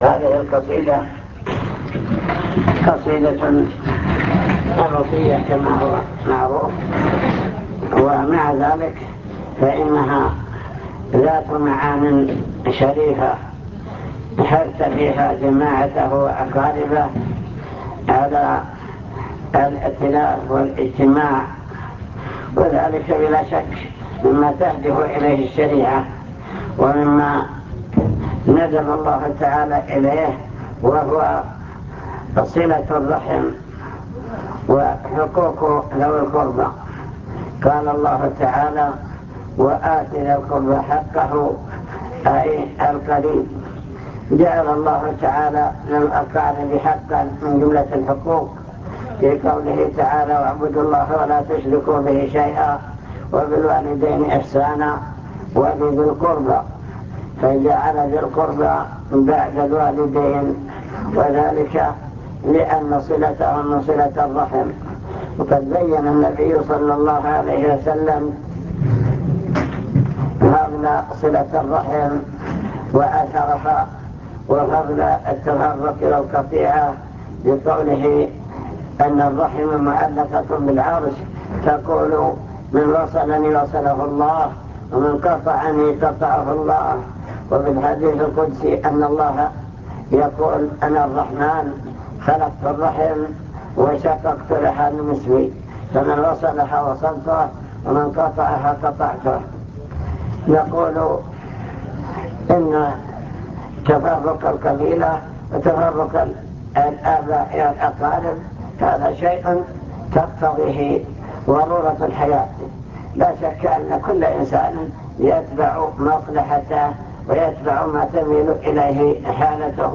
المكتبة الصوتية  تسجيلات - محاضرات ودروس  محاضرة بعنوان من يرد الله به خيرا يفقهه في الدين